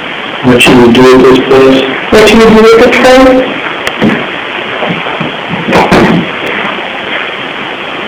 In theory, it's the recording of ghost voices.
If it was just someone clearing their throat, it's pretty creepy-sounding and we think someone would have had to ask "what was that!?"